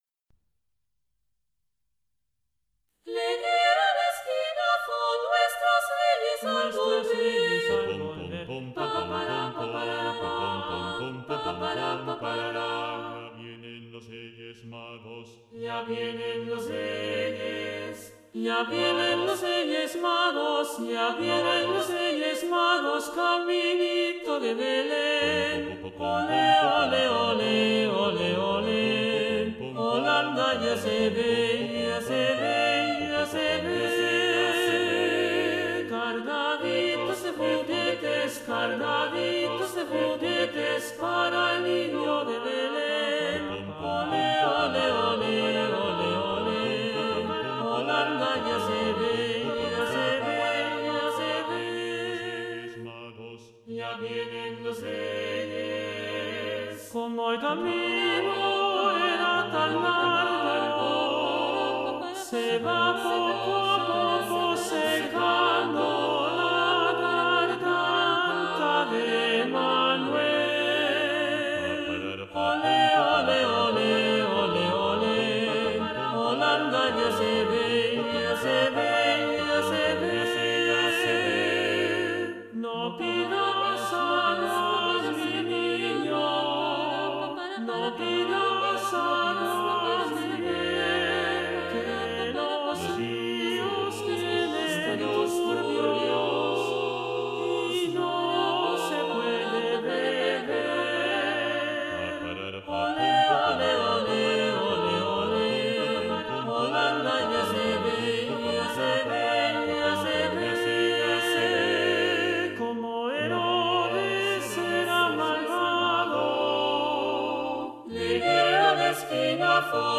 Title: Ya vienen los Reyes Magos Composer: Abraham González Ponce Lyricist: Traditional Number of voices: 7vv Voicing: SATTBB & T solo Genre: Sacred, Villancico
Language: Spanish Instruments: A cappella
Musical arrangement in modern styles of a Spanish traditional Christmas song named the same.
Score information: A4, 6 pages, 845 kB Copyright: CC BY NC ND Edition notes: Audio provided is Artificial intelligenced-based Cantamus app performance.